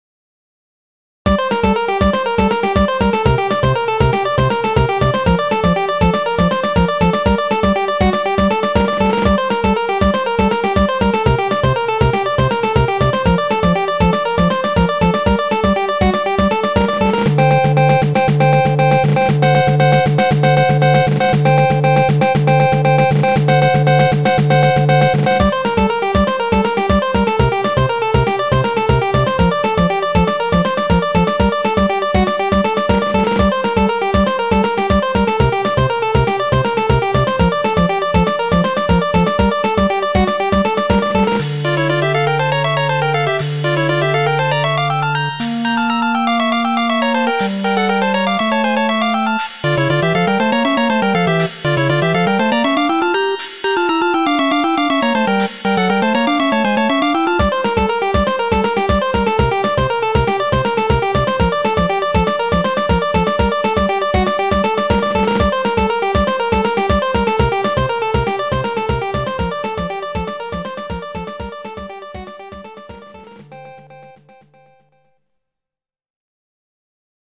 GS音源。